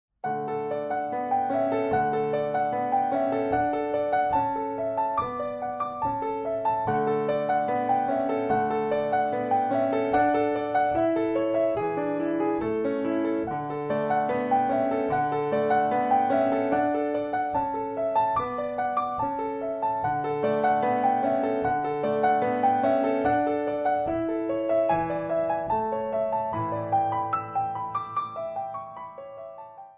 12 Klavierstücke, mittelschwer
Besetzung: Klavier